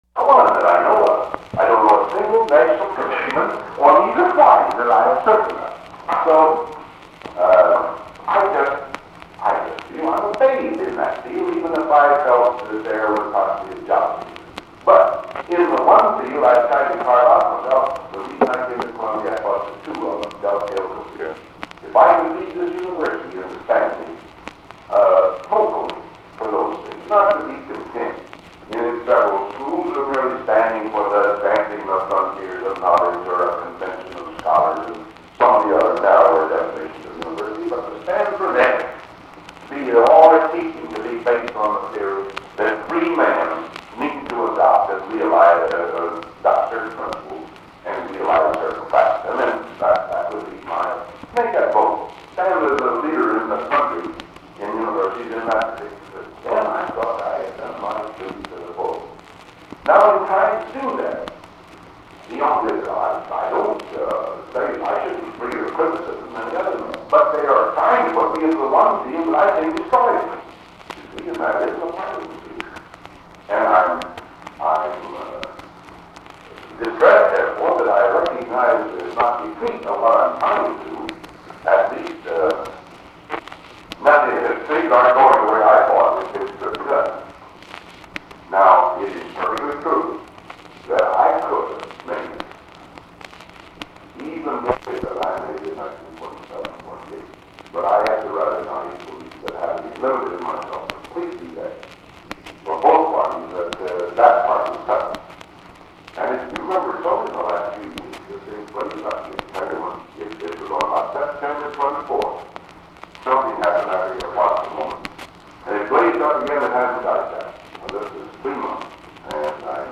Meetings with John Foster Dulles and Unidentified Man—December 20, 1949
Secret White House Tapes